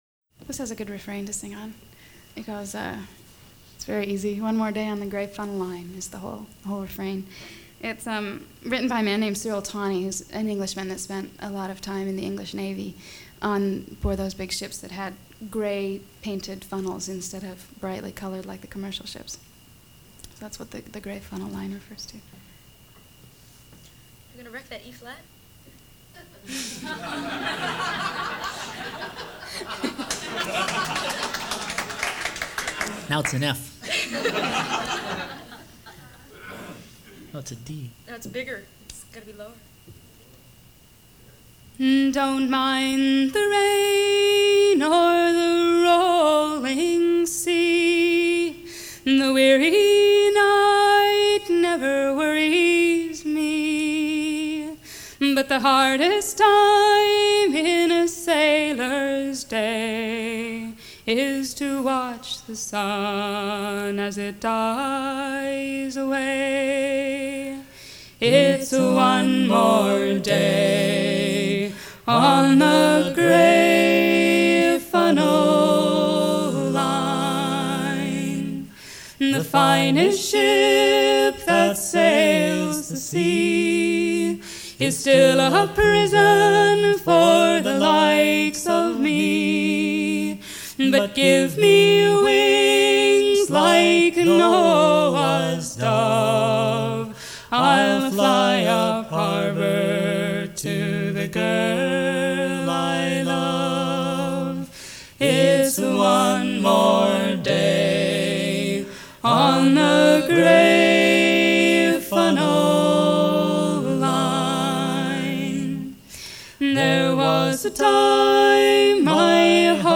Live performances